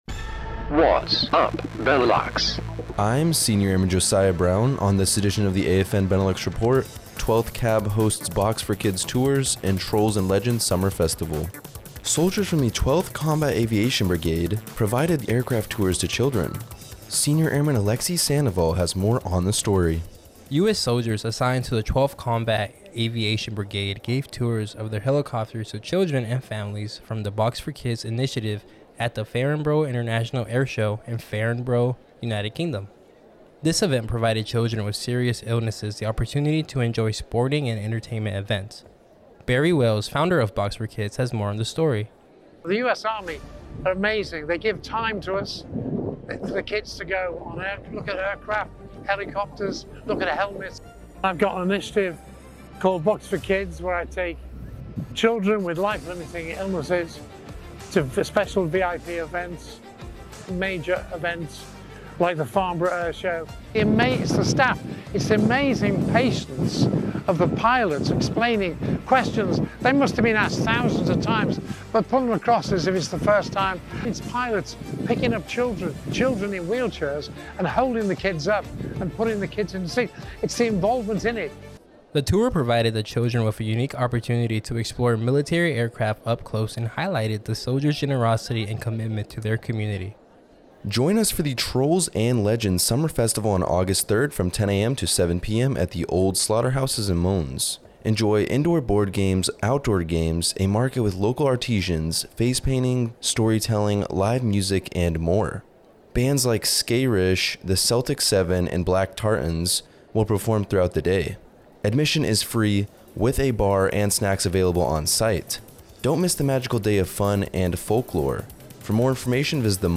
American Forces Network Benelux reports on current and past events, Soldiers from 12th Combat Aviation Brigade provided a tour for children and the Trolls & Legends Summer Festival event, July 30, 2024, for a radio broadcast out of SHAPE, Belgium.